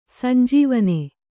♪ sanjīvane